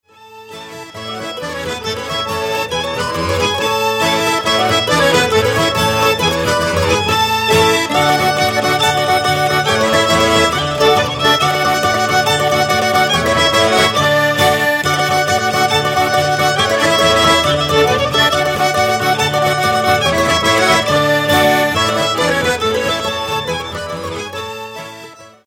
Dalle musiche da ballo della tradizione popolare emiliana,
DEMO mp3 - Frammenti brani registrazione live